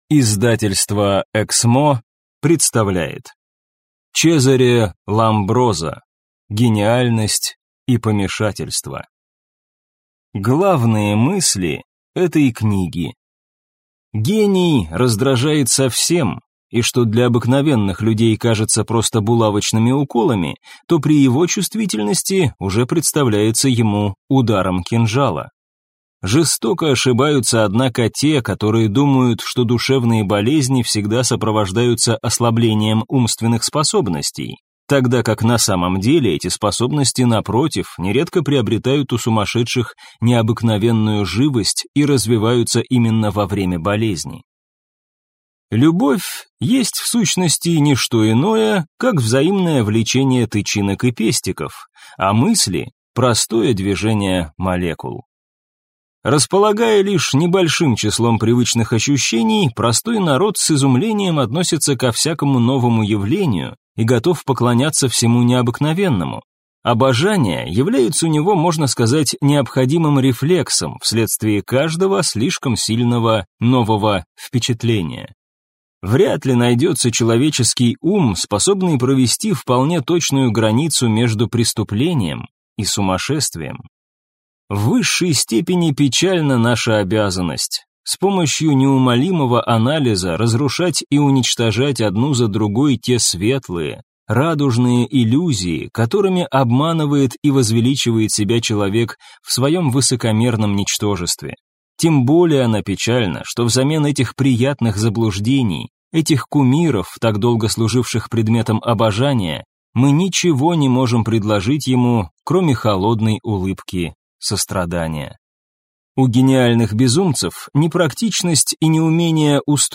Аудиокнига Гениальность и помешательство | Библиотека аудиокниг